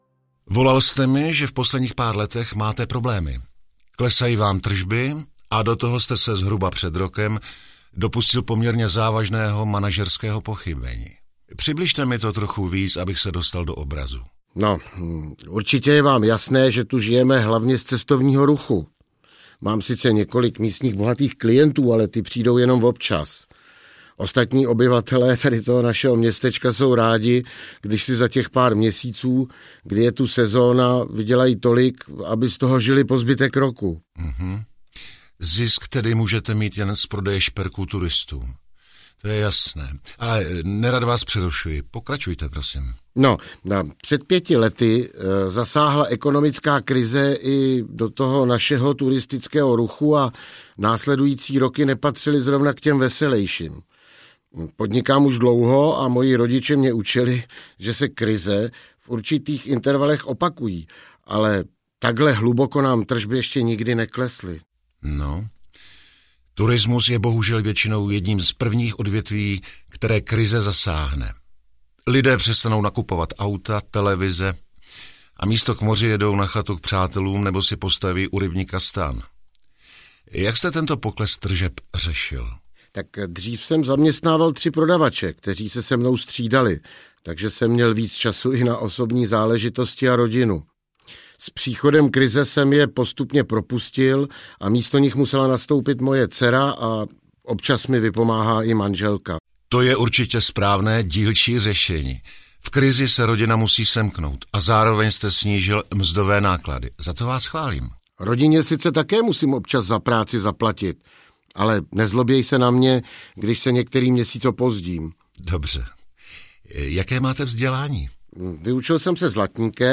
Krotitel rizik podnikání zasahuje: Zlatnictví audiokniha
Ukázka z knihy
Spojení zajímavých příběhů s hlasy známých českých herců podtrhuje atraktivitu celého projektu. V druhém díle, který se odehrává ve známém přímořském letovisku se dozvíte, jak krotitel pomohl místnímu zlatníkovi dostat firmu z krize.
• InterpretAlexej Pyško, Milan Štaindler